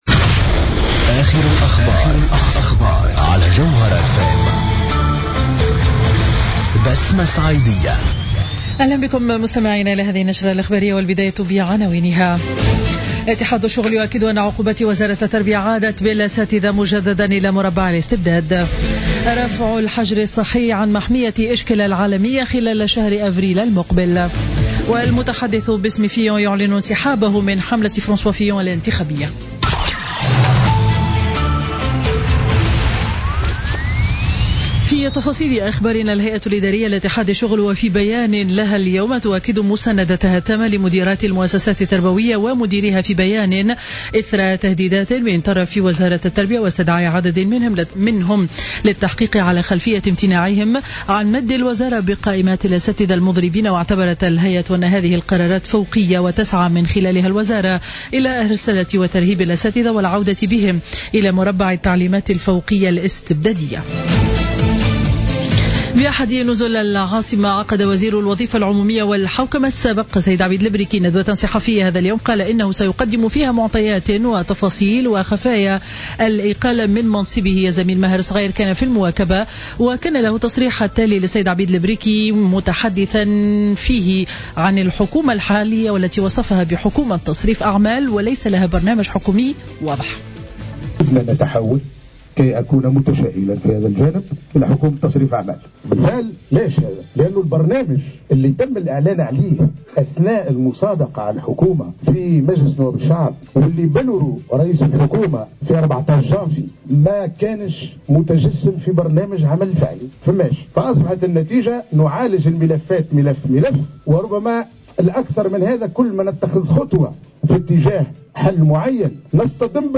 نشرة أخبار منتصف النهار ليوم الجمعة 3 مارس 2019